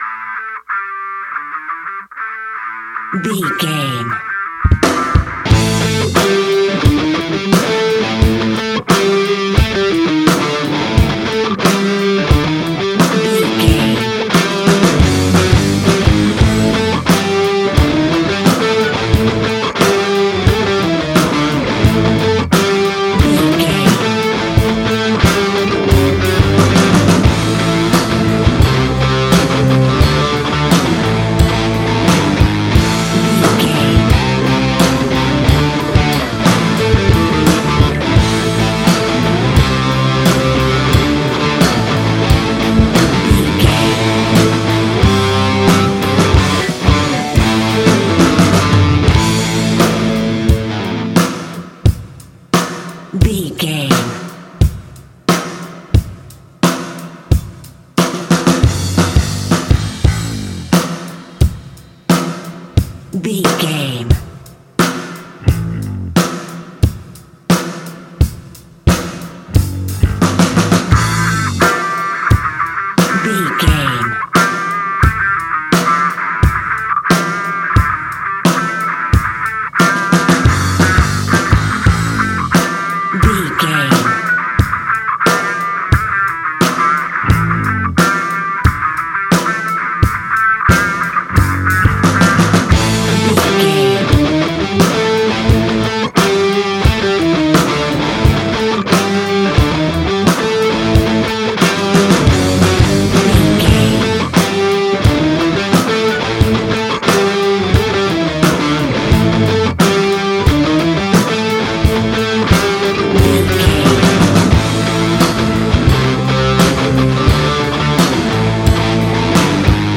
Ionian/Major
A♭
hard rock
blues rock
distortion